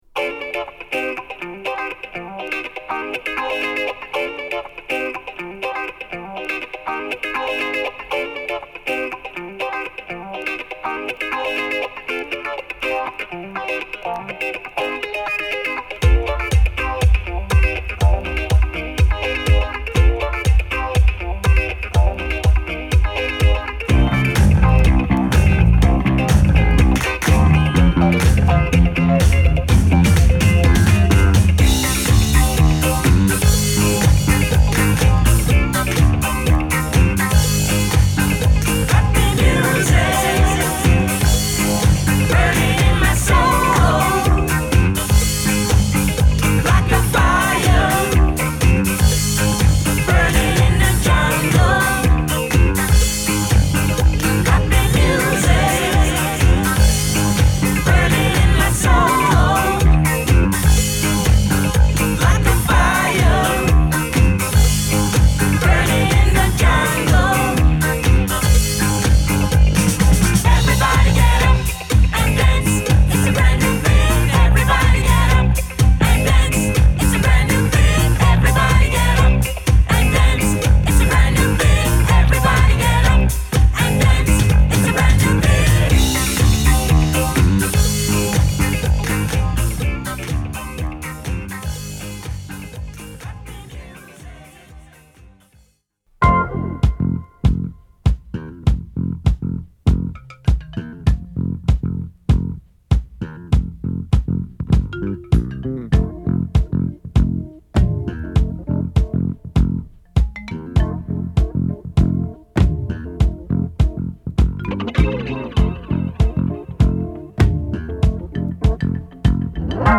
re-edit
Reggae Boogie dub edit